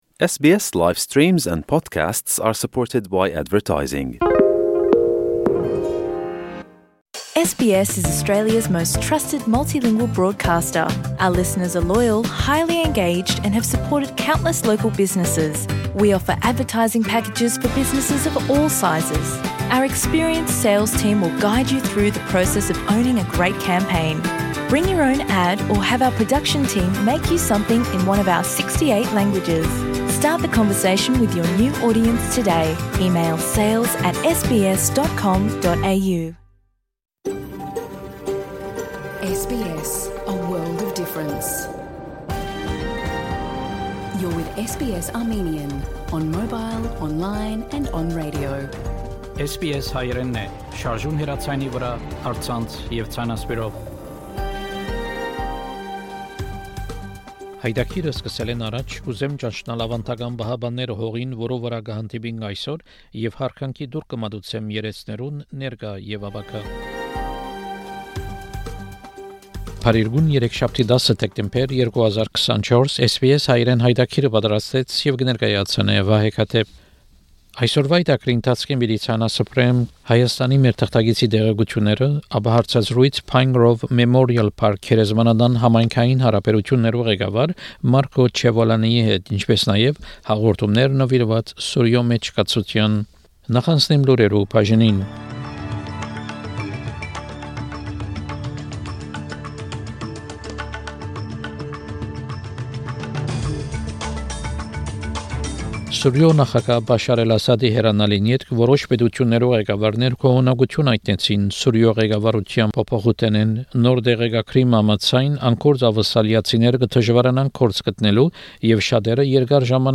SBS Հայերէնի աւստրալիական և միջազգային լուրերը քաղուած 10 Դեկտեմբեր 2024 յայտագրէն: SBS Armenian news bulletin from 10 December program.